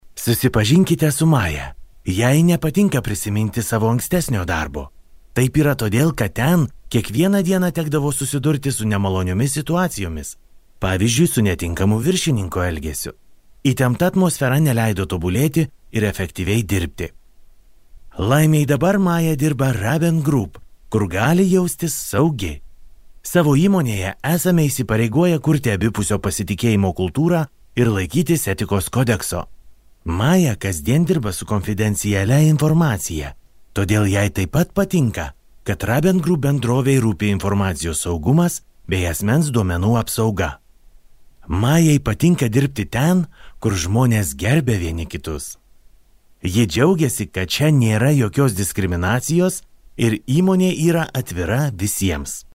男立陶宛01 立陶宛语男声 广告宣传片全能 大气浑厚磁性|沉稳|娓娓道来